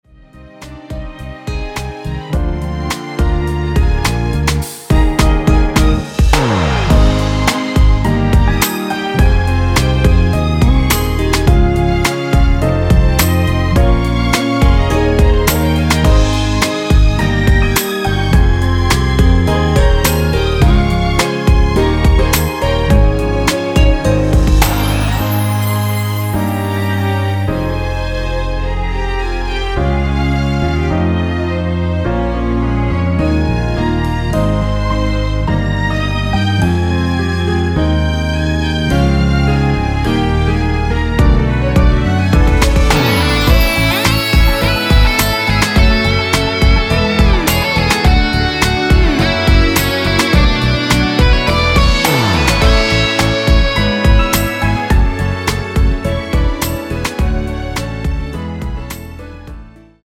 원키(1절+후렴)으로 진행되는 MR입니다.
Ab
앞부분30초, 뒷부분30초씩 편집해서 올려 드리고 있습니다.
중간에 음이 끈어지고 다시 나오는 이유는